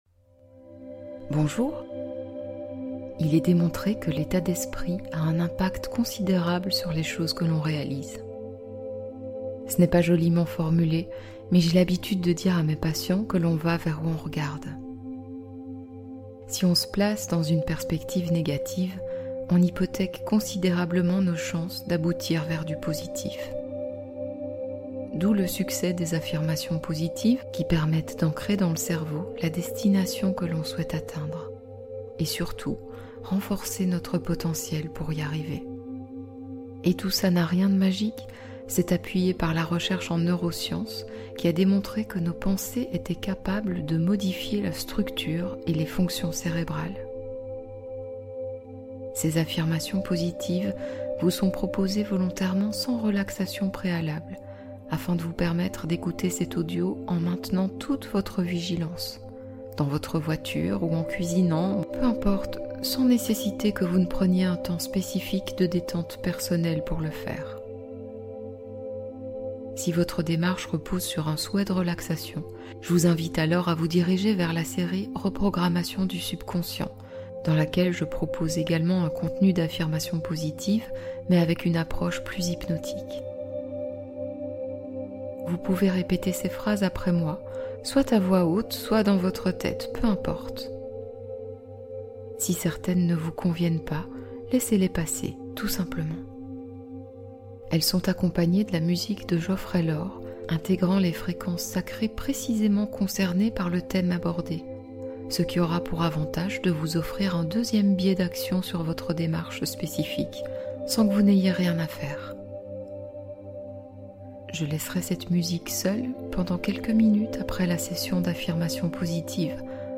Liberté Affective : Fréquence 528 Hz pour guérir les liens de dépendance